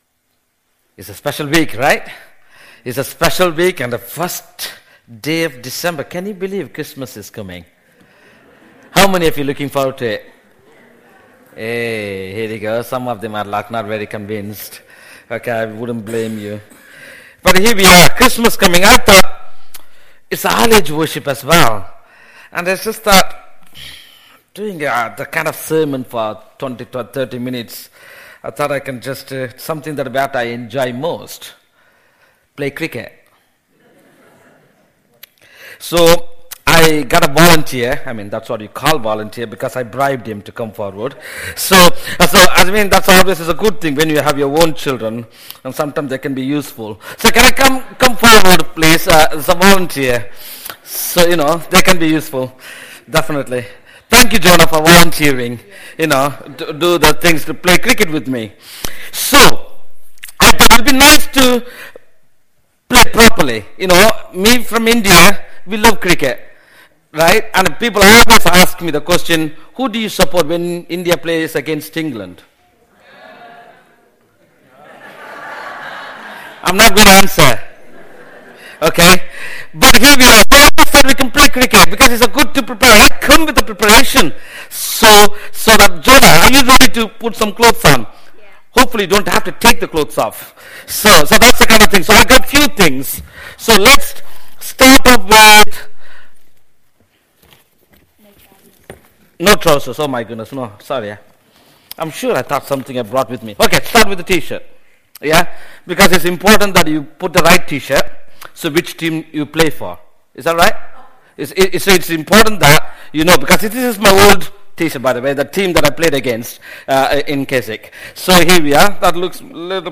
Passage: Jeremiah 33:14-16, 1 Thessalonians 3:9-13 Service Type: All Age Worship
12-01-Sermon.mp3